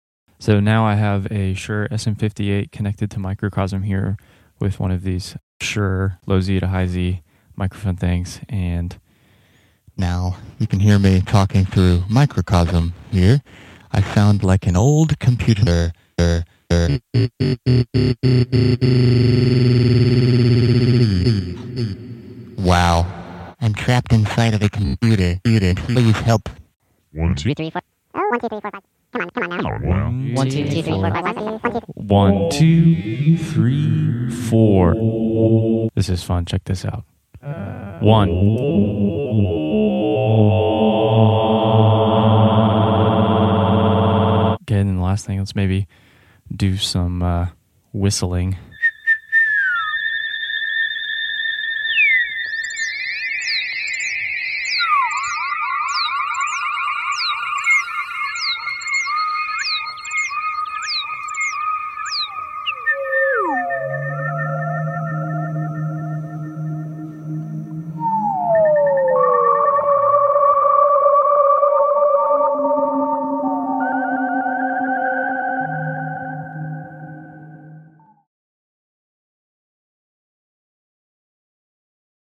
Having some fun with voice sound effects free download
Having some fun with voice + Microcosm 🎤